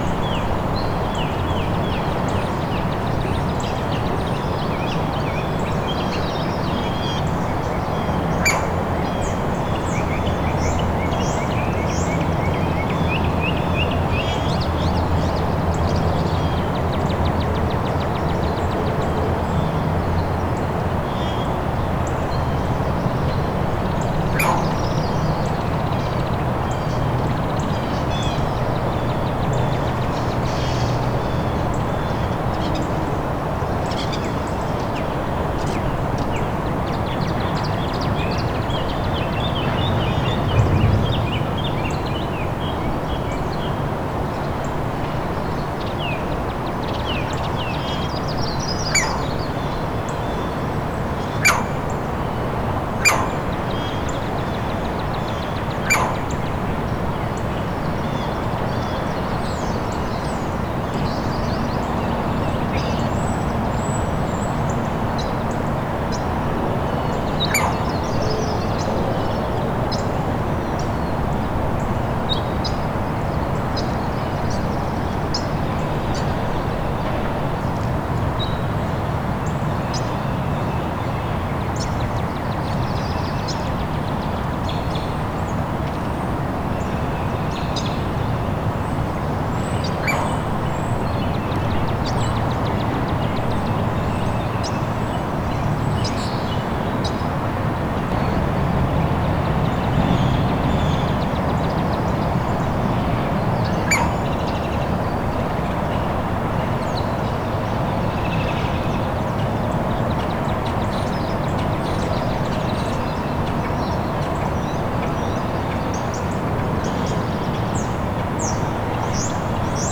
You may need to turn up the volume to hear its calls. I was close, but wasn’t using my best equipment. The bird alternated between preening, gazing about, and calling.
Green Heron
green-heron-41421.wav